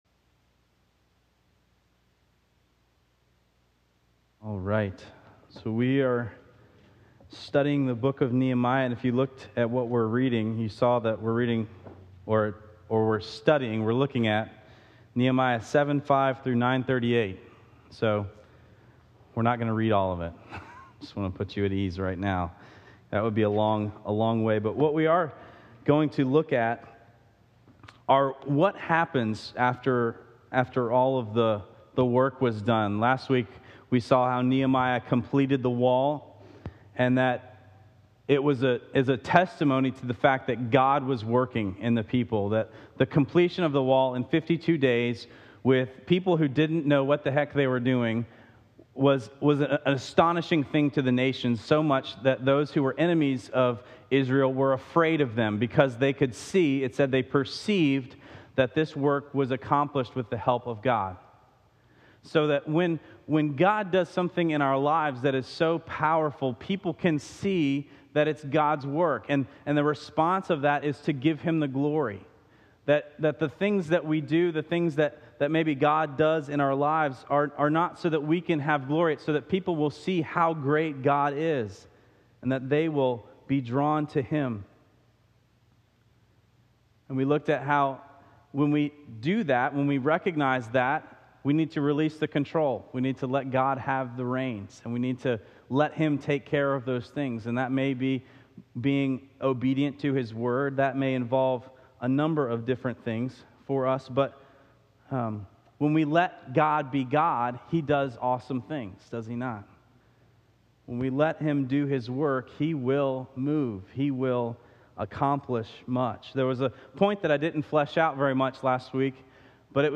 Last week we ended the sermon with a point that I didn’t really flesh out, that God is going to keep working on you…